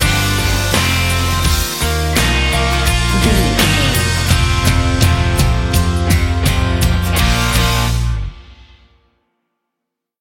Ionian/Major
E♭
acoustic guitar
electric guitar
drums
bass guitar